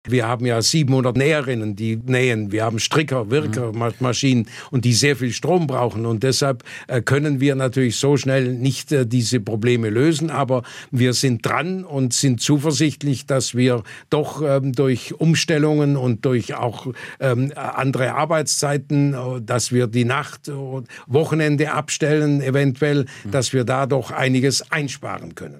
Die immens hohen Energiepreise deckt Grupp momentan durch Rücklagen, wie er im SWR-Interview erzählte.